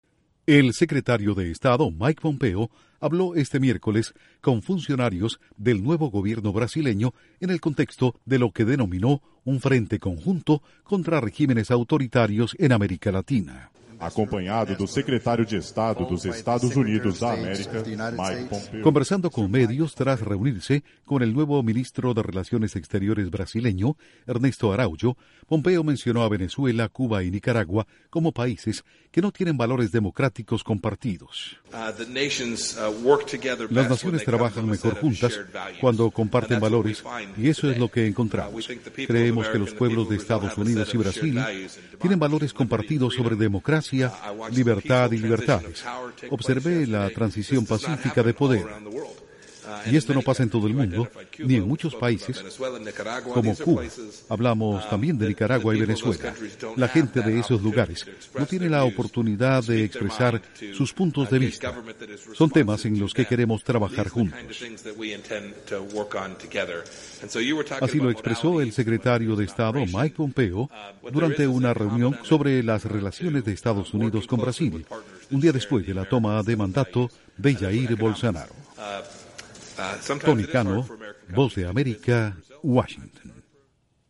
Duración: 1:24 2 audios de Mike Pompeo/Secretario de Estado